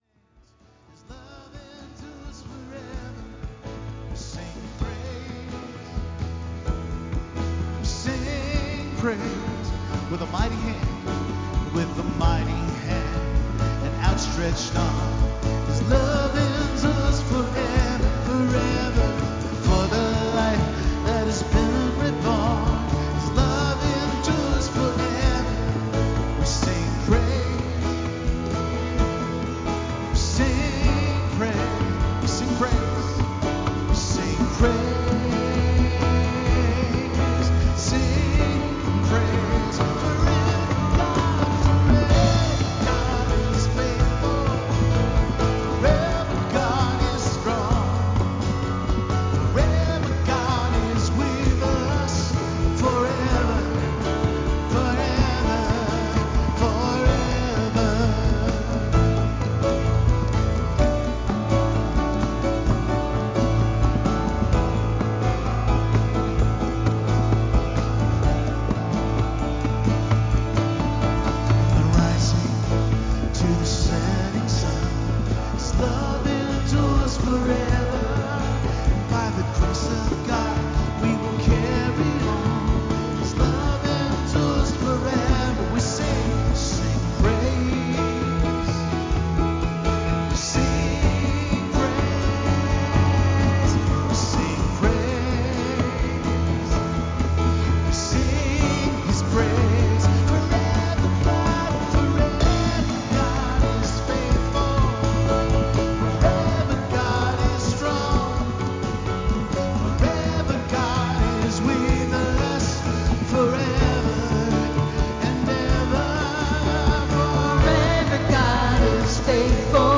Reflecting on Sin and Redemption: Lessons from Romans Sermon – Grace Fellowship